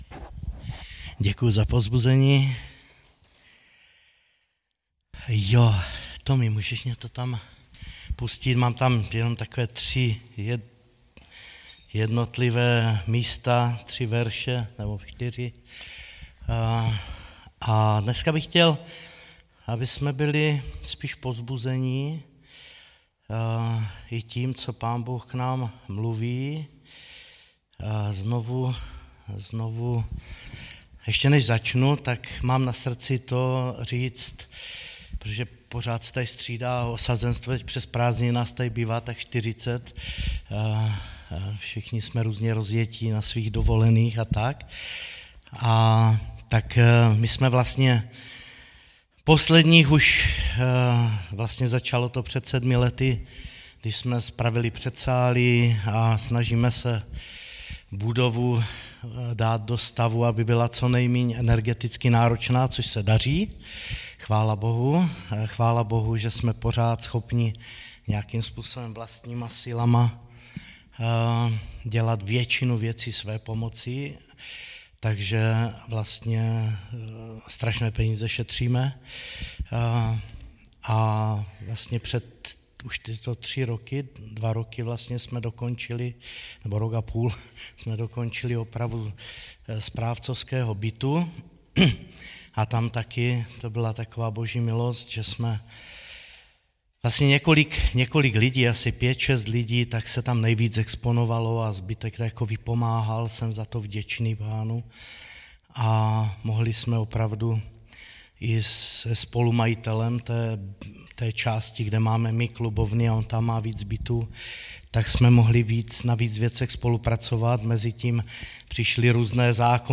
Kázání - cervenec_2023 - Apoštolská církev, sbor Olomouc